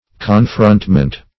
Search Result for " confrontment" : The Collaborative International Dictionary of English v.0.48: Confrontment \Con*front"ment\, n. The act of confronting; the state of being face to face.